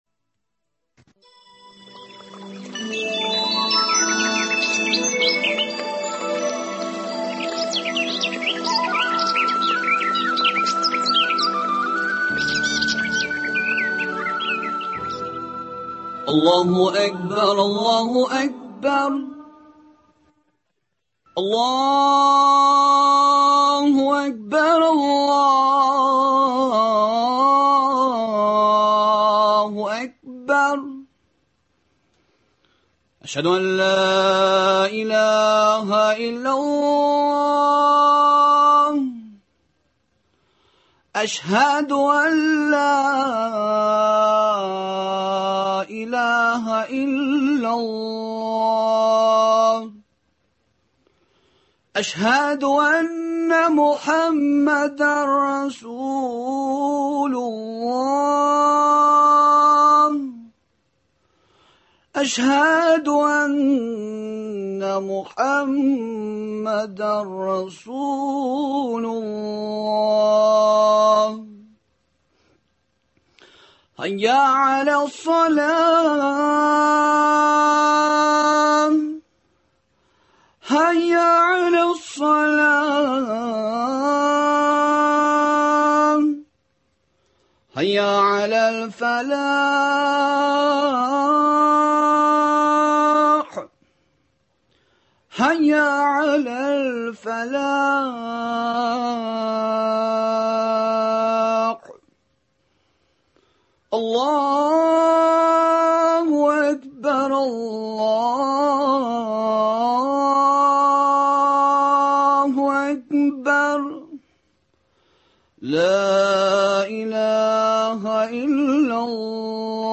Студия кунагы